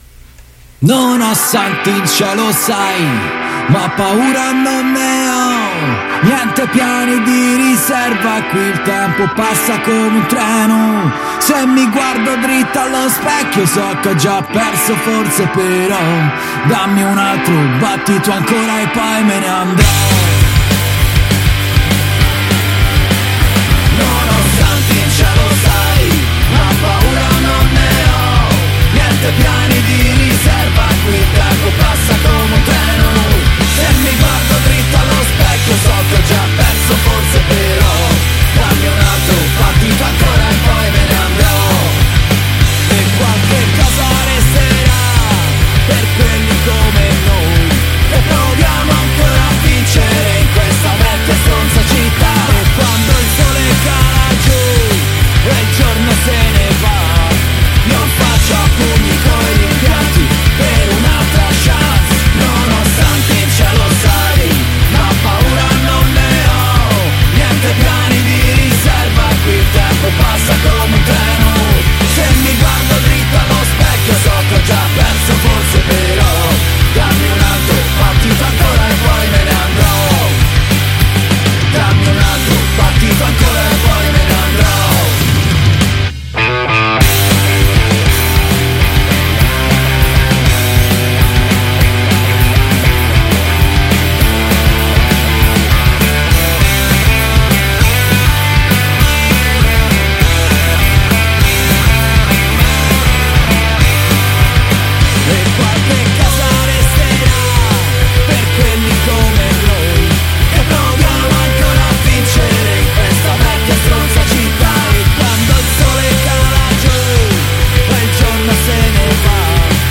Gli Ultimi: strappare lungo il punk-rock | Intervista